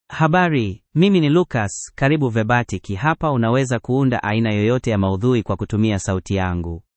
MaleSwahili (Kenya)
Lucas is a male AI voice for Swahili (Kenya).
Voice sample
Male
Lucas delivers clear pronunciation with authentic Kenya Swahili intonation, making your content sound professionally produced.